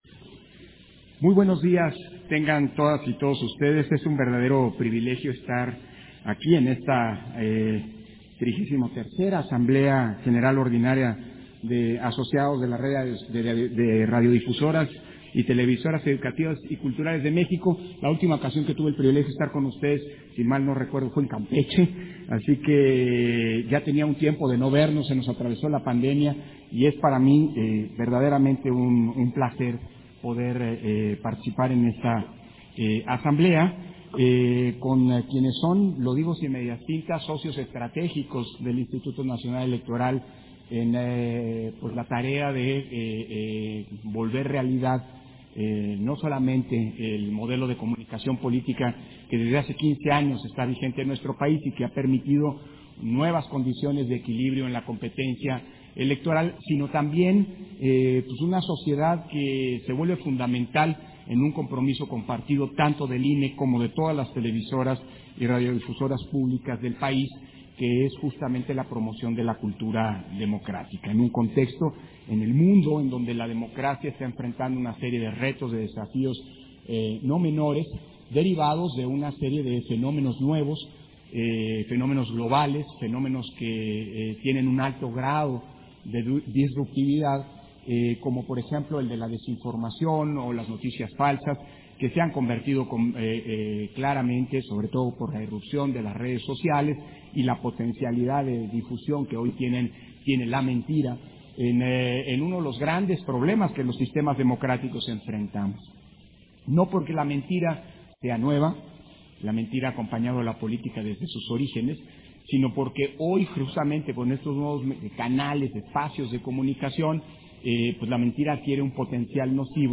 250522_AUDIO_INTERVENCIÓN-CONSEJERO-PDTE.-CÓRDOVA-XXXIII-ASAMBLEA-GENERAL-ORDINARIA-DE-ASOCIADOS-DE-LA-RED - Central Electoral